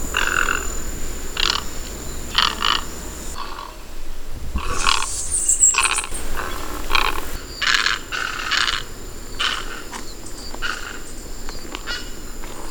Great Egret
Ardea alba
VOICE: Squawks when disturbed, but usually silent away from nesting colonies.